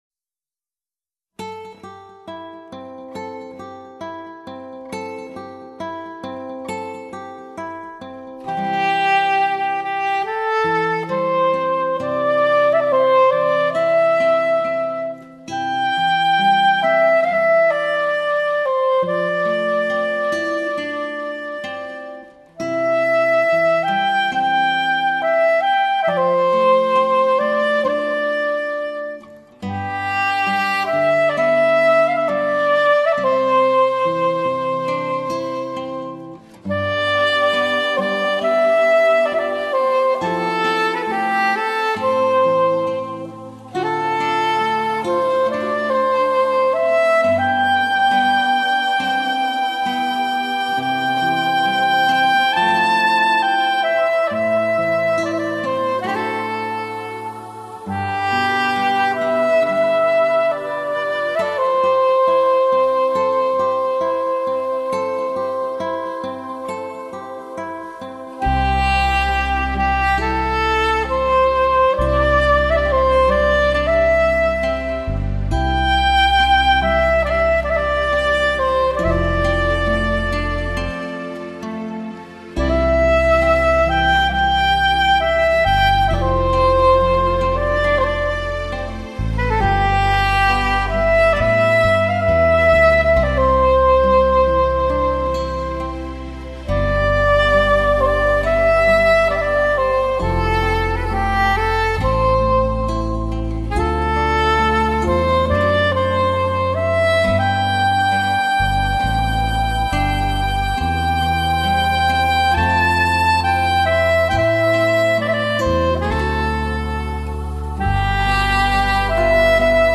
萨克斯
温柔的、深情的、诱人的、狂放的、轻松的......一并邀您尽情的沉醉其中。
乐曲风格柔和易听，音色与质感超凡
效果离奇HI-Fi